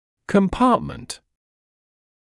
[kəm’pɑːtmənt][кэм’паːтмэнт]отделение, отсек; камера